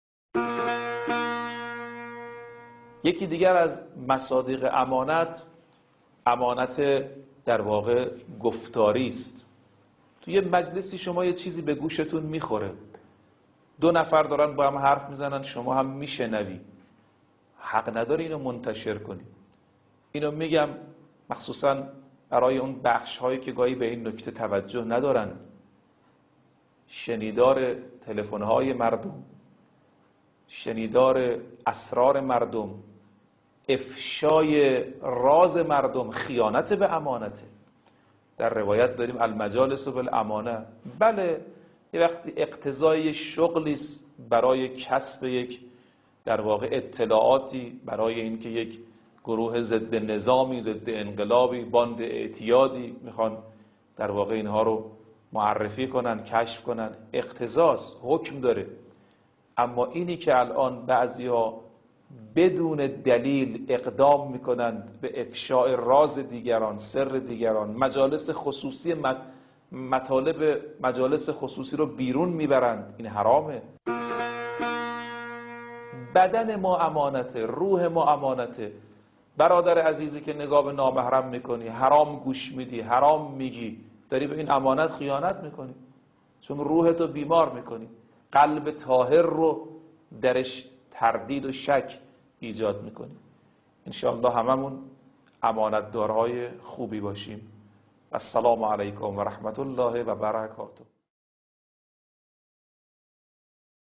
بخشی از سخنرانی حجت الاسلام ناصر رفیعی با موضوع امانت در گفتار